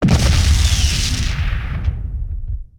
enemymissile.ogg